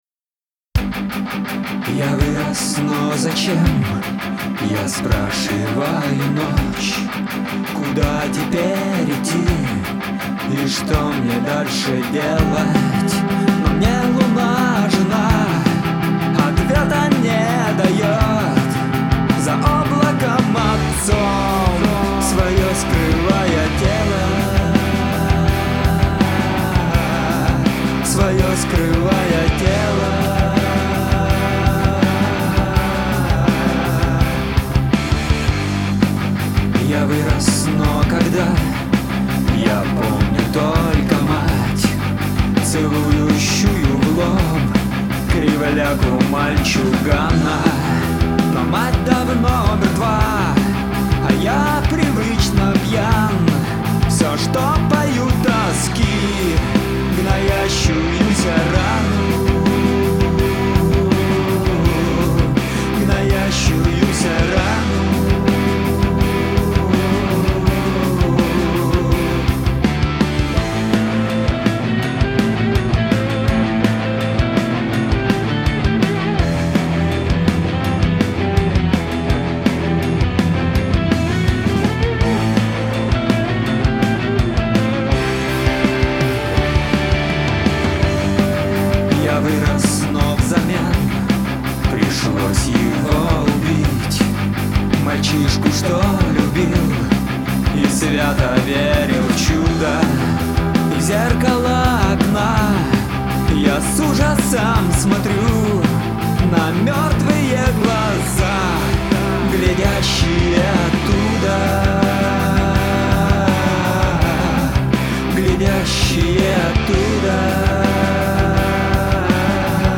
вокалы, бэки, гитары, бас, клавишные, программирование барабанов